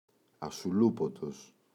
ασουλούπωτος [asu’lupotos] – ΔΠΗ